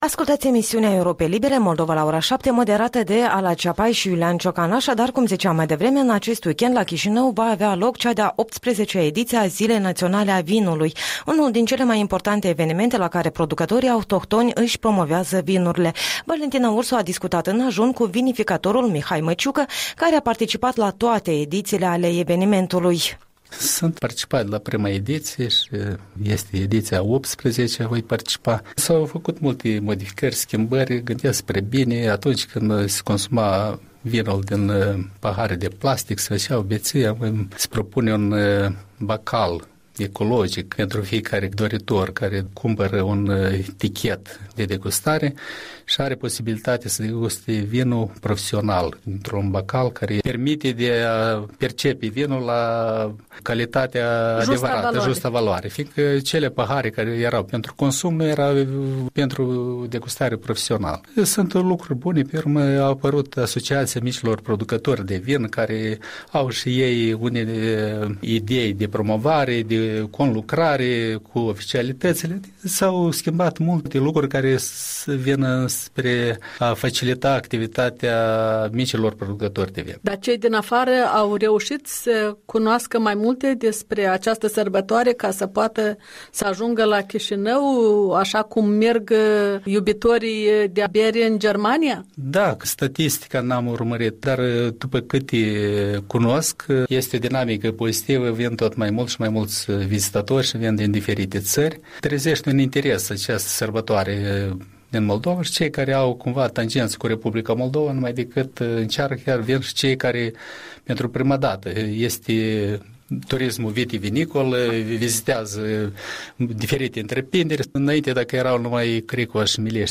În ajunul celei de-a 18-a ediții a Zilei Naționale a Vinului, un interviu cu un producător de vinuri apreciat.